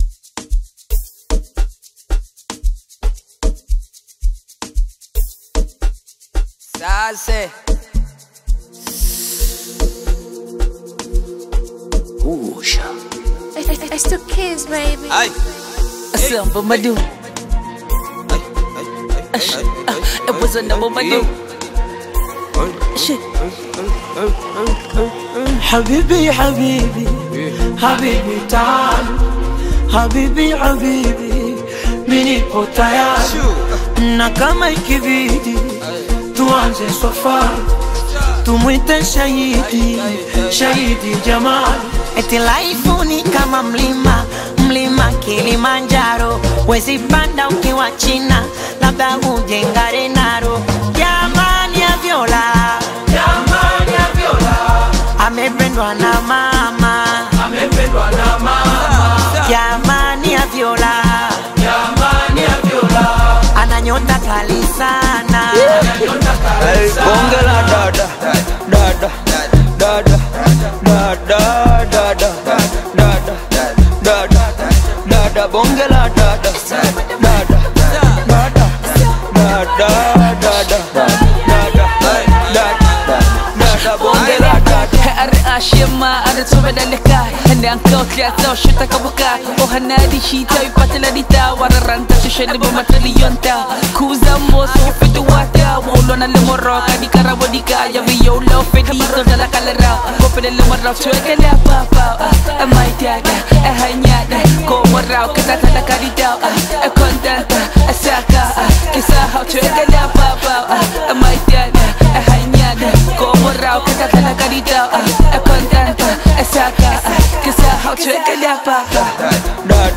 smooth Bongo Flava/Amapiano single